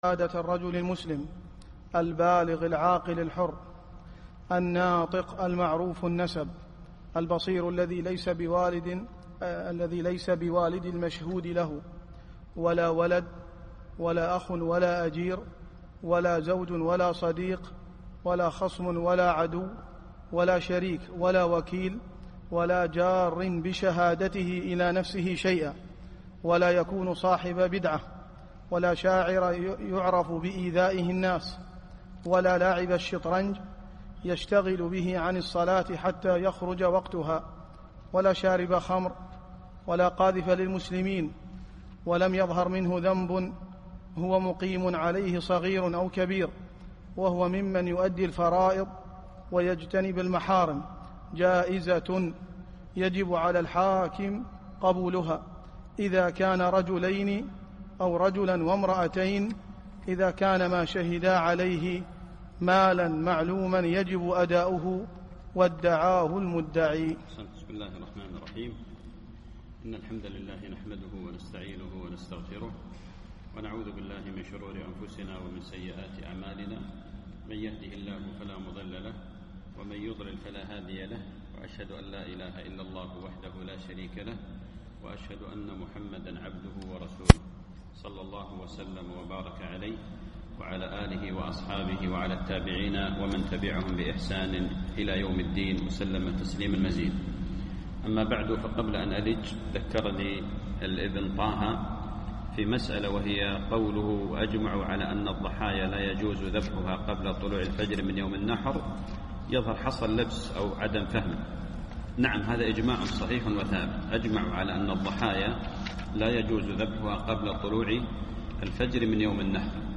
الدرس السادس عشر